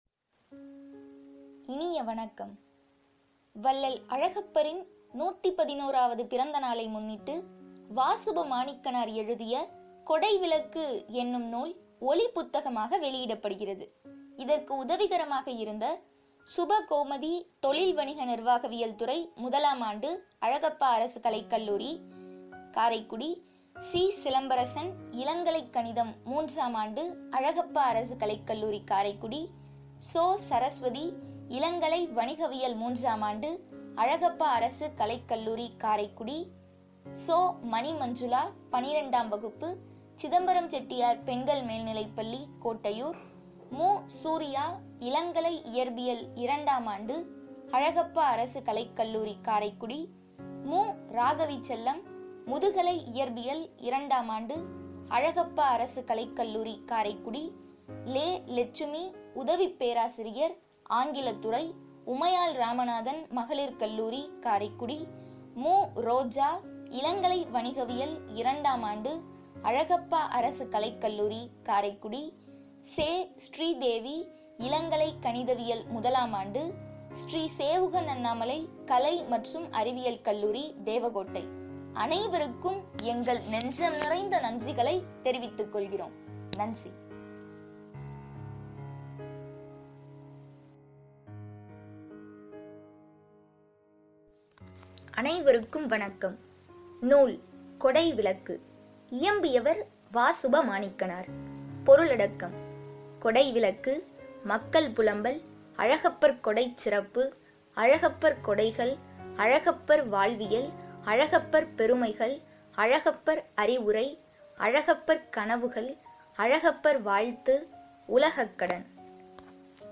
கொடைவிளக்கு ஒலிப்புத்தகம்